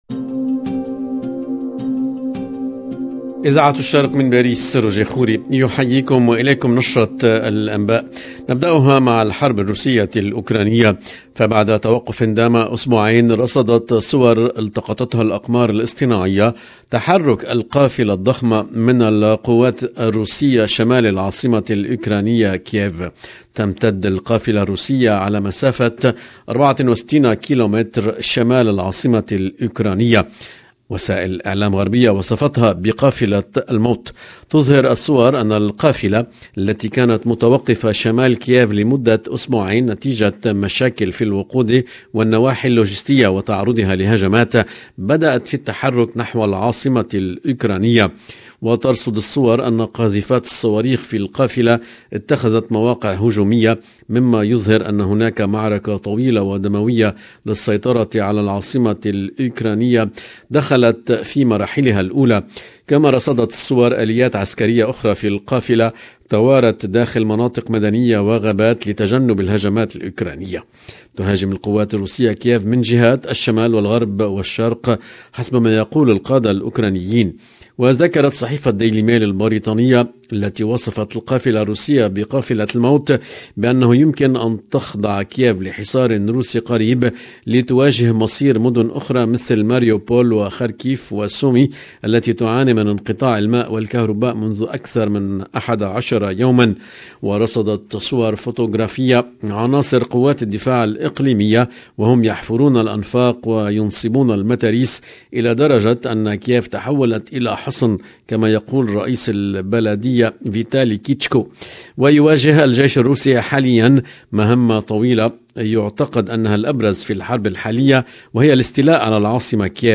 Tunisie 12 mars 2022 - 16 min 37 sec LE JOURNAL DU SOIR EN LANGUE ARABE DU 12/03/22 LB JOURNAL EN LANGUE ARABE العناوين الأقمار الاصطناعية ترصد صورا لتحرك القافلة الضخمة من القوات روسية شمالي العاصمة الأوكرانية كييف بعد توقف دام أسبوعين...